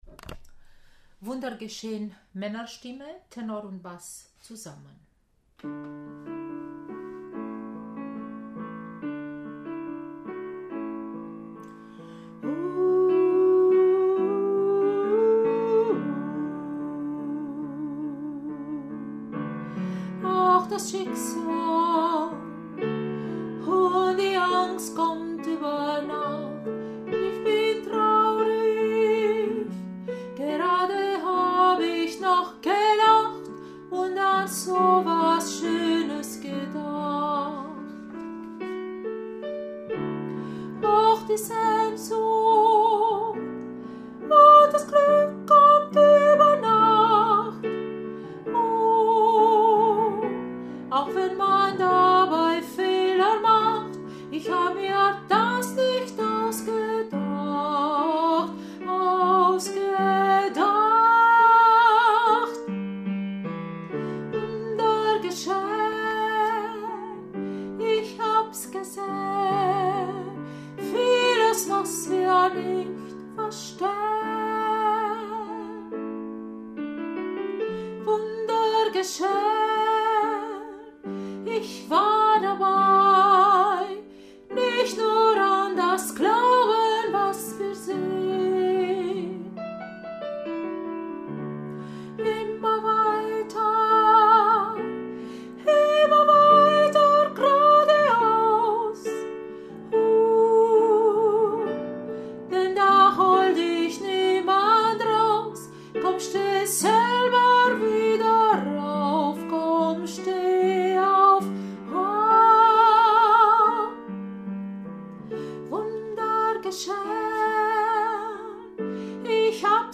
Wunder-geschehen-Männer-TB-neu.mp3